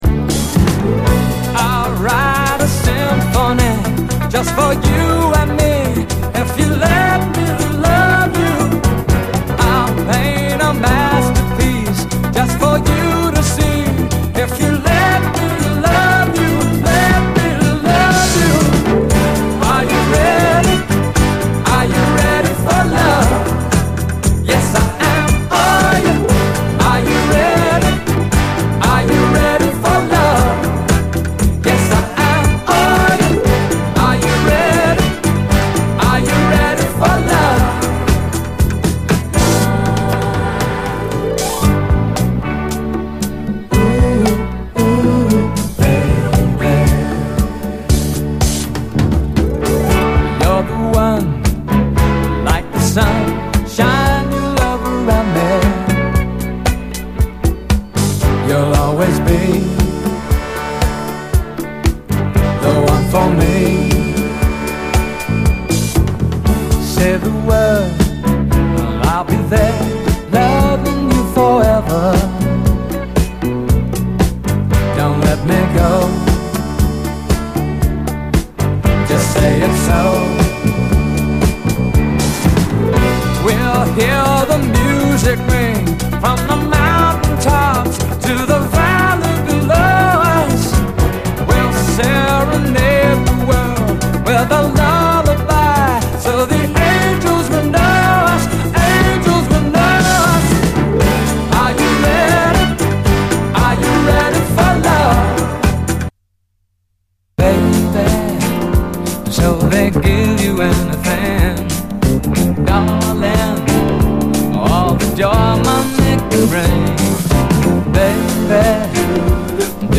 シャッフルするフィンガー・スナッピン・クロスオーヴァー・ソウル